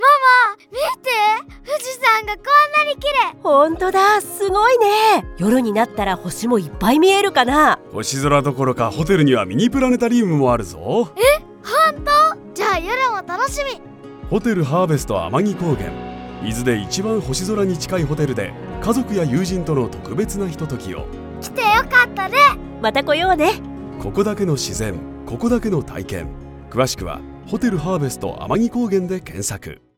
音声CM ホテルハーヴェスト天城高原（東急リゾーツ＆ステイ株式会社様）
東急リゾーツ_また来ようね編-BGM①.mp3